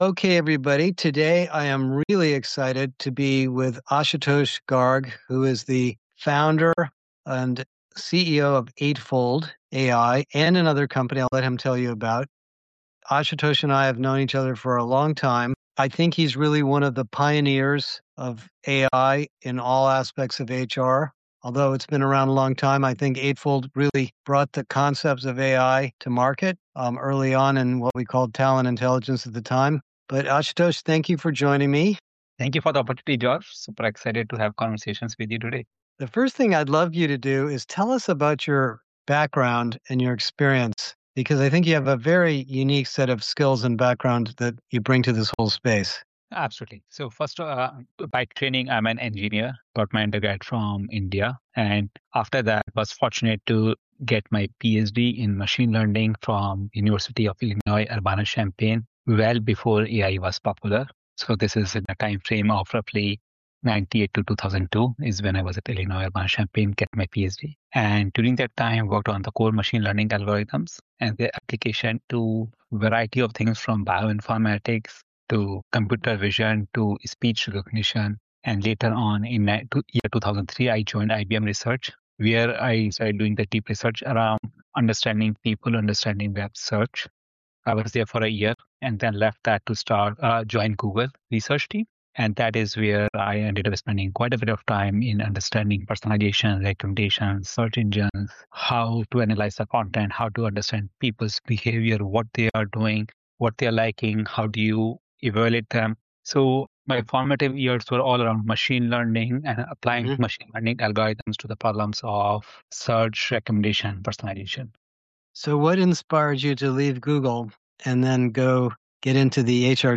This week I share my conversation